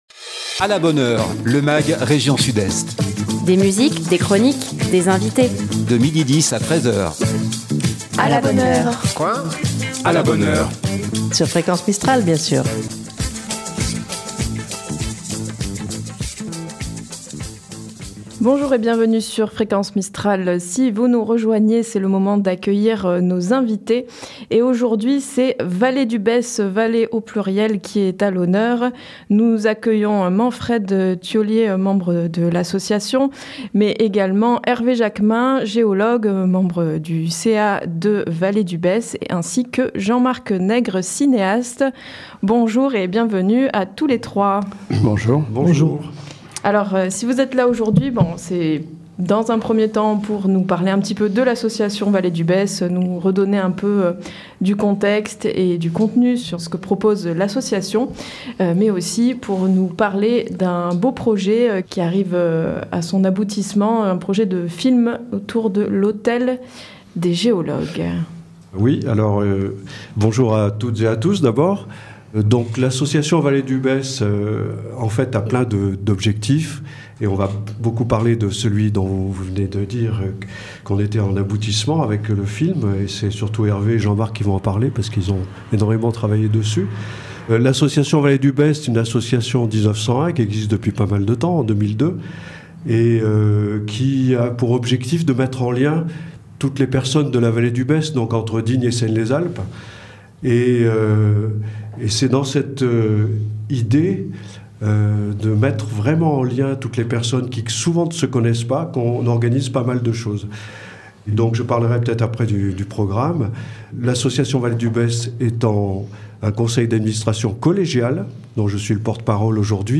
étaient les invités du magazine régional "A la bonne heure"